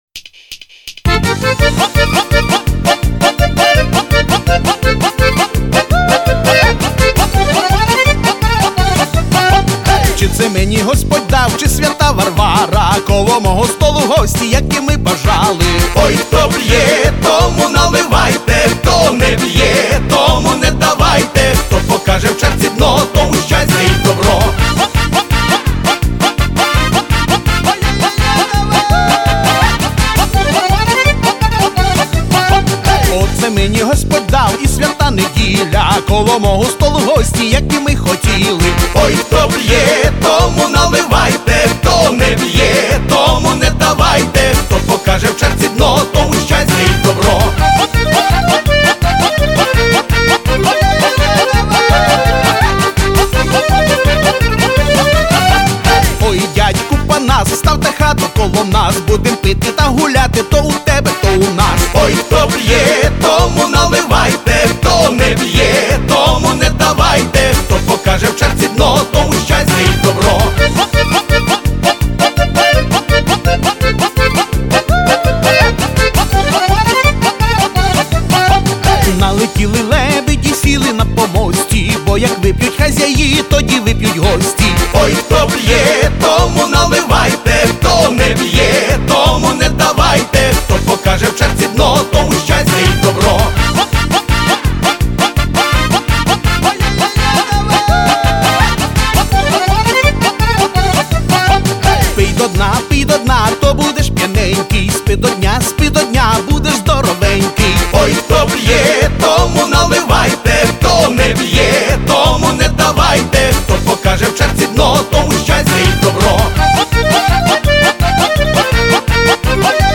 ukranski_vesilni_pisni___oj__hto_p__tomu_nalivajte_z3_fm.mp3